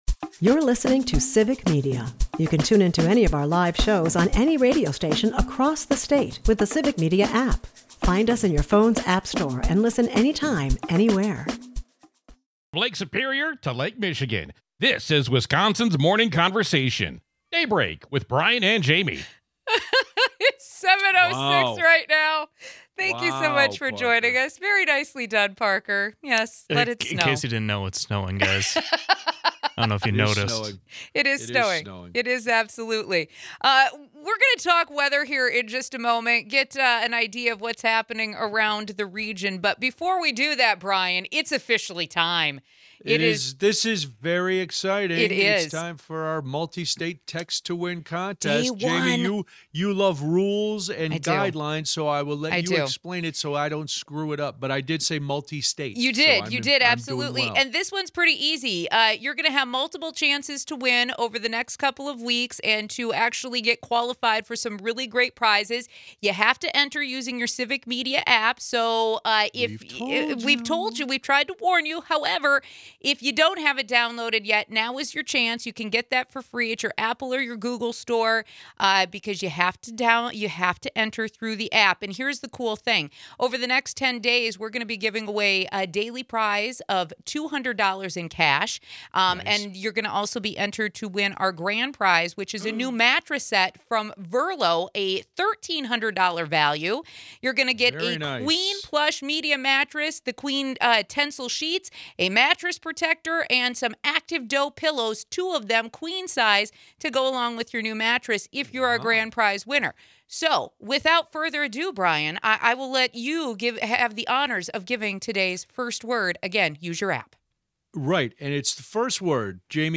We kick off the hour talking to some guests from up north where the snow storm is even worse. In non-weather Wisconsin news, Republican candidate for governor Tom Tiffany weighed in on absentee voting. Wisconsin is one of over a dozen states suing the Trump administration (again).